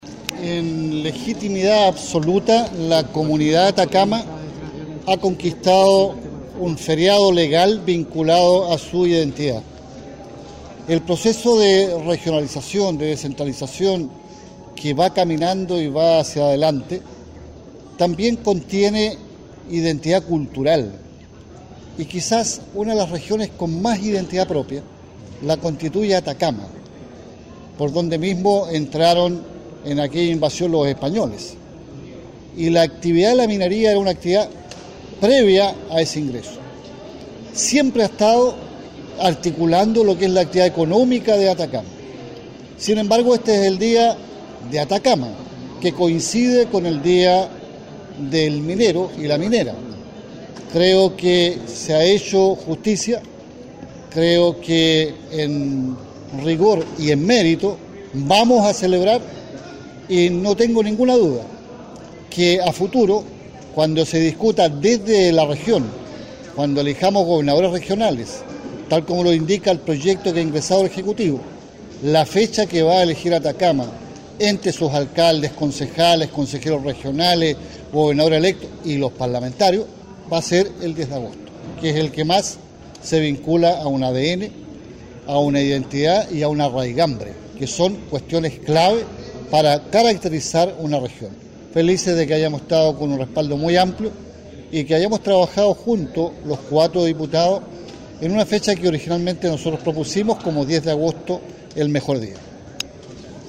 Escuche al diputado Carmona: